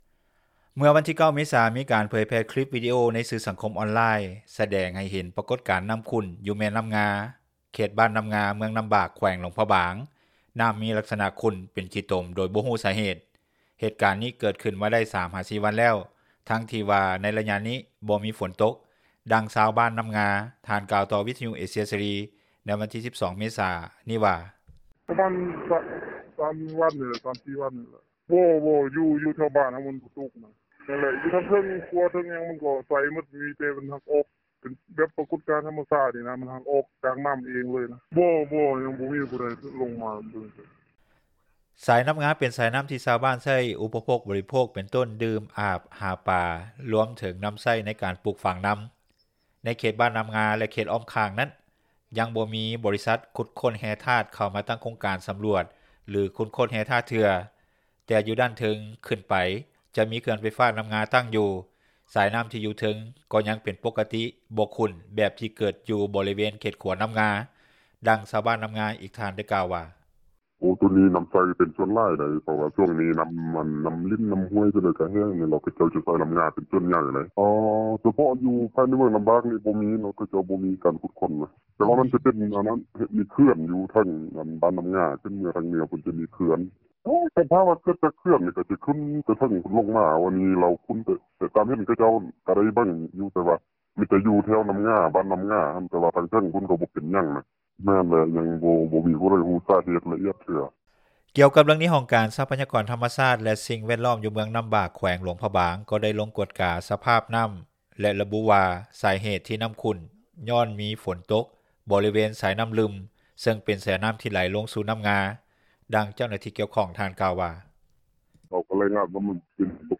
ດັ່ງຊາວບ້ານນໍ້າງາ ອີກທ່ານໄດ້ກ່າວວ່າ:
ດັ່ງເຈົ້າໜ້າທີ່ກ່ຽວຂ້ອງທ່ານກ່າວວ່າ:
ດັ່ງຊາວບ້ານ ຢູ່ບ້ານຄົກຕົມ ທ່ານກ່າວໃນມື້ດຽວກັນວ່າ: